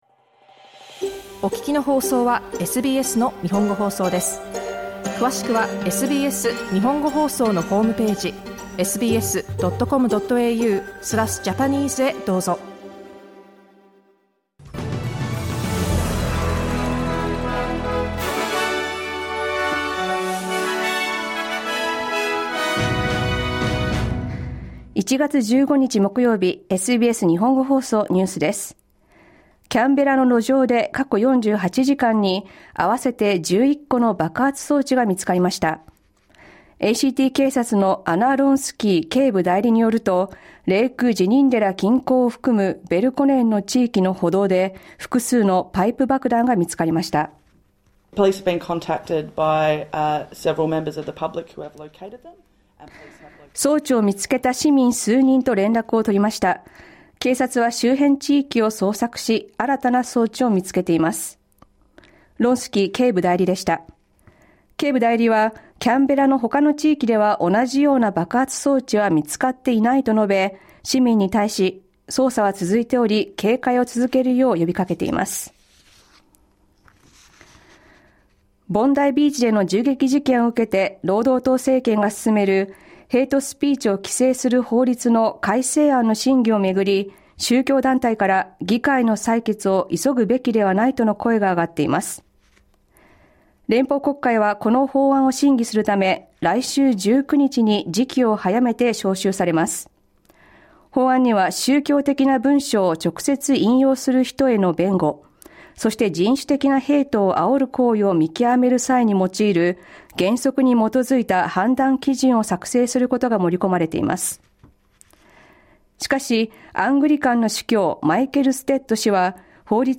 A total of eleven explosive devices have been found on the streets of Canberra in the past 48 hours. Faith group leaders are calling for a vote on hate speech reforms to be pushed back. Eleven major fires continue to burn across Victoria, with the blazes so far tearing through more than 400,000 hectares. News from today's live program (1-2pm).